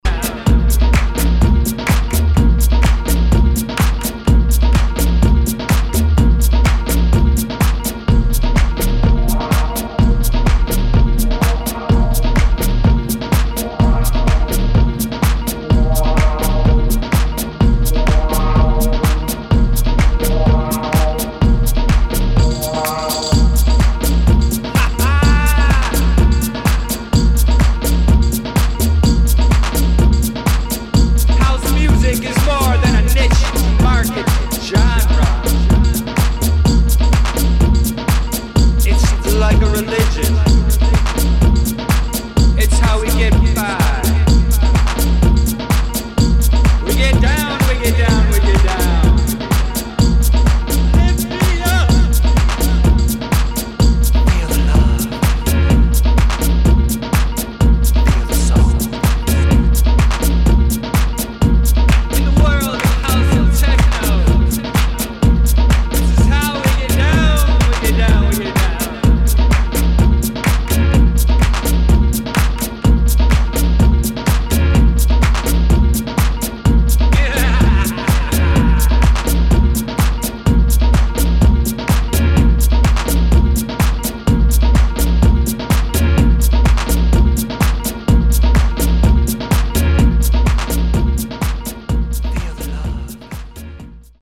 [ TECHNO / DEEP HOUSE ]